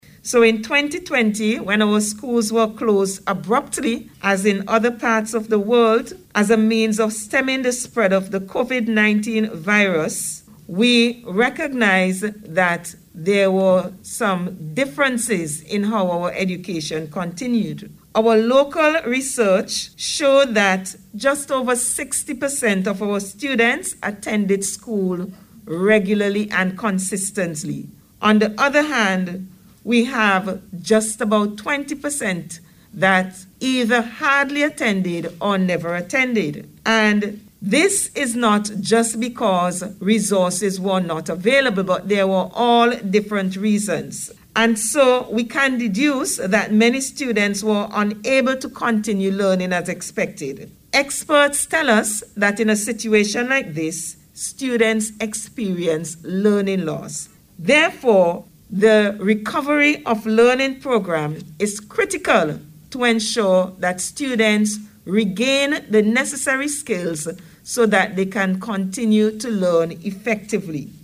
during a ceremony to mark the handing over of learning resources to Schools, to be used in the programme.